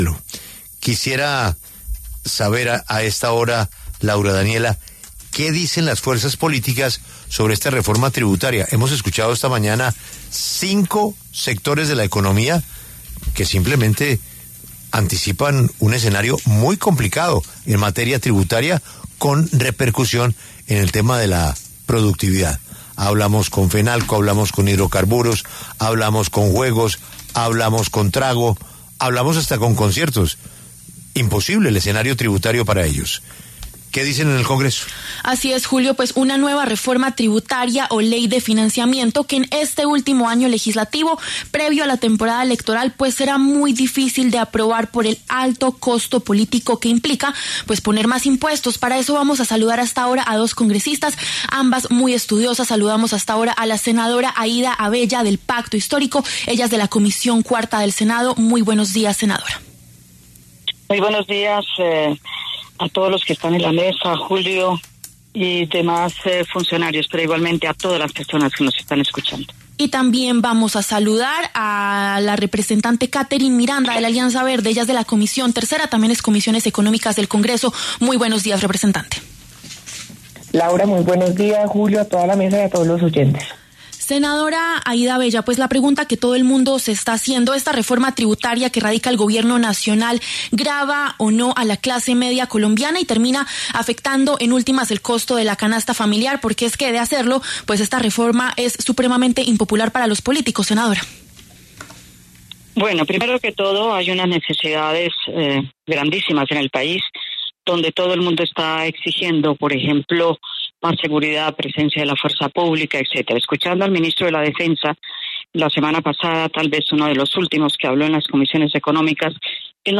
¿Reforma tributaria golpea a la clase media colombiana? Debaten Katherine Miranda y Aída Avella
Las congresistas Aída Avella, del Pacto Histórico, y Katherine Miranda, de la Alianza Verde, pasaron por los micrófonos de La W.